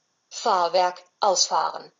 Ich mache wenn ich ne kurze Pause haben will einfach zwei oder drei "leer" Zeichen rein.